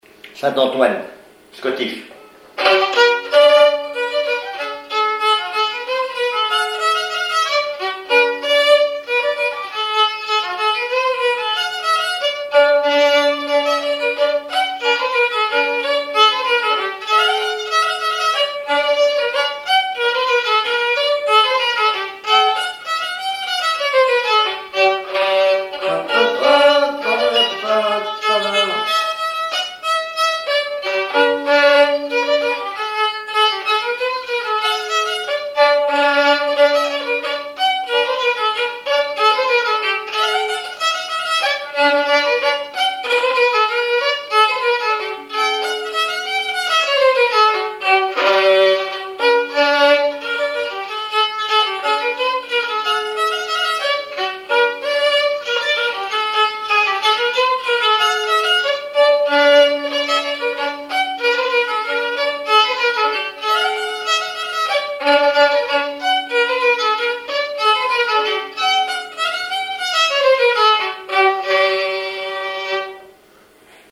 Mémoires et Patrimoines vivants - RaddO est une base de données d'archives iconographiques et sonores.
violoneux, violon
danse : scottich trois pas
Pièce musicale inédite